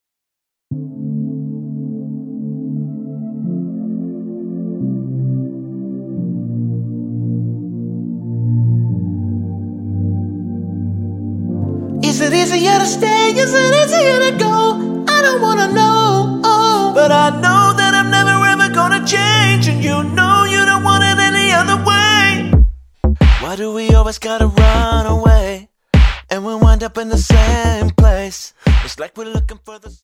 --> MP3 Demo abspielen...
Tonart:Dm Multifile (kein Sofortdownload.
Die besten Playbacks Instrumentals und Karaoke Versionen .